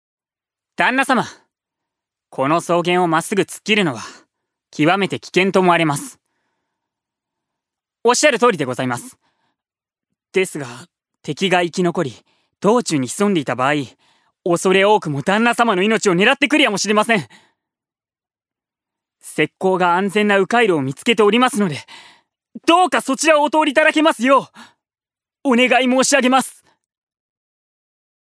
ジュニア：男性
2. セリフ２